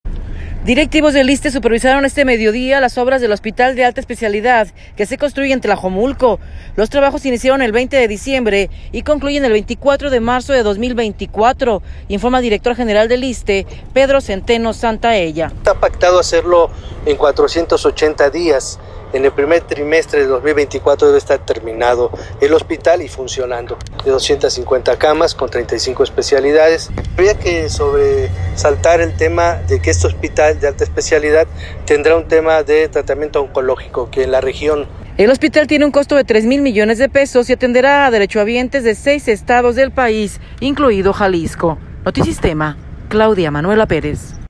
Directivos del ISSSTE supervisaron este mediodía las obras del Hospital de Alta Especialidad que se construye en Tlajomulco. Los trabajos iniciaron el 20 de diciembre y concluyen el 24 de marzo de 2024, informa el director general del ISSSTE, Pedro Zenteno Santaella.